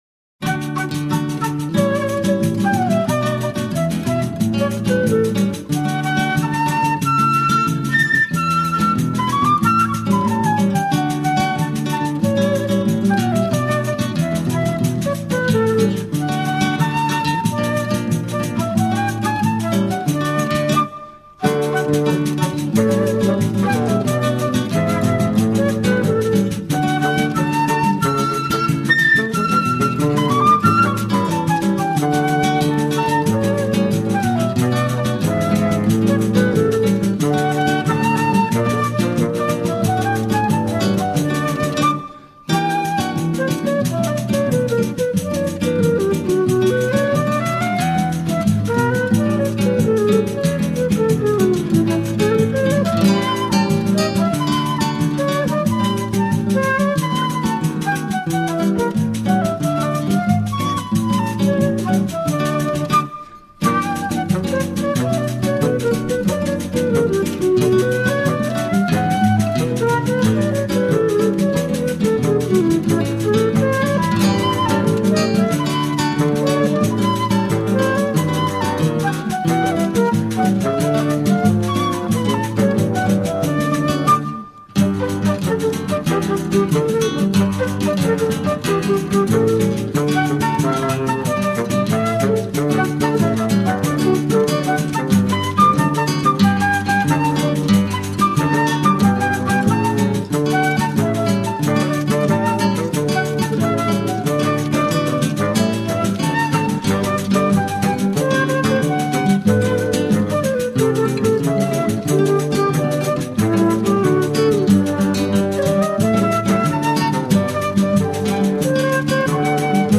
Brazilian contemporary and traditional flute.
with flute, acoustic guitar, fagot and percussion